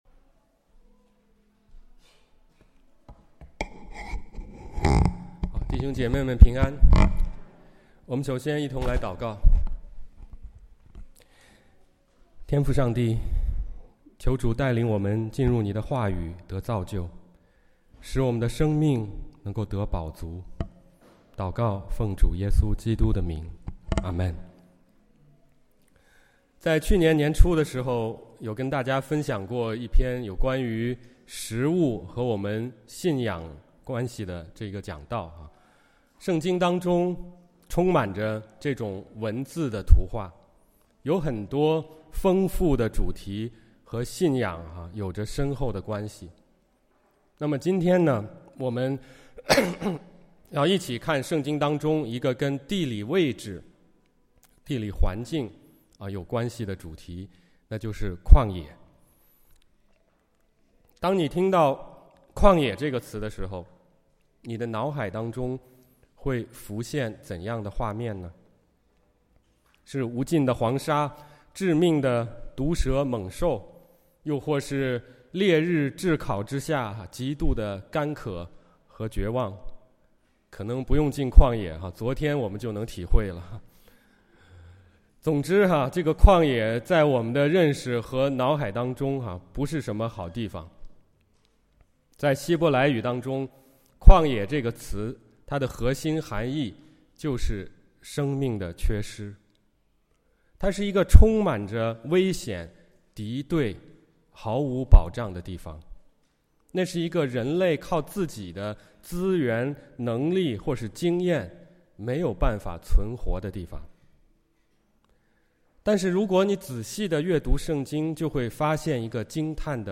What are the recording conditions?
930 am Mandarin Service - 11 Jan 2026